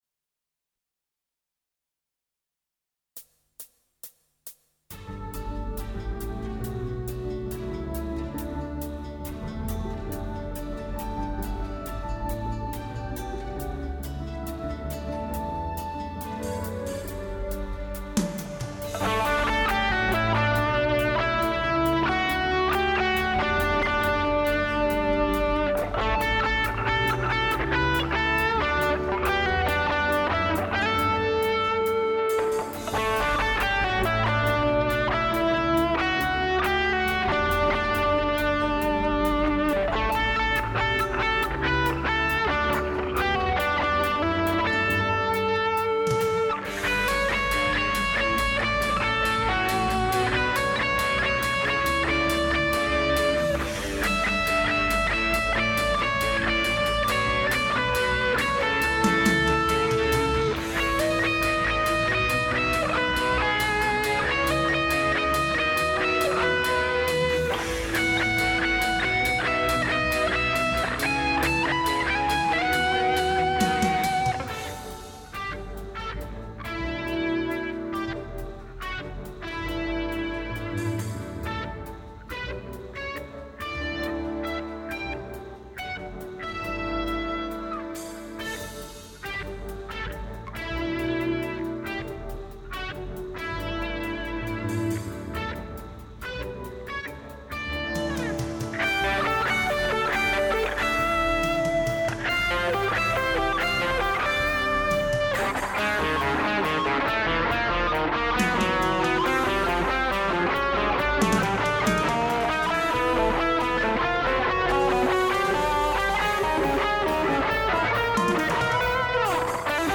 LISTEN TO THE FIRST JAZZ FUSION PLAY ALONG
● FULL BAND MP3 PLAY ALONG INCLUDED AND QR CODE PLAY